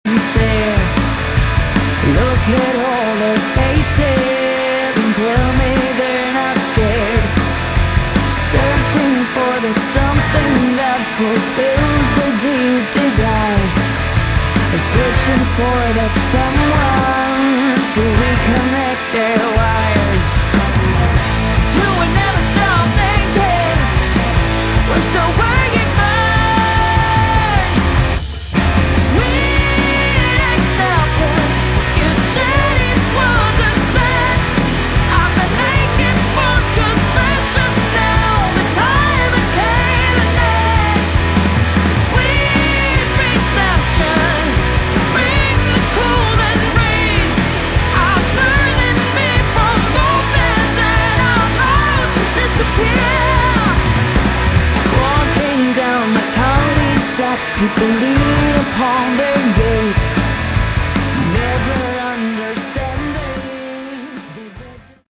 Category: Hard Rock
Vocals
Guitars
Bass
Drums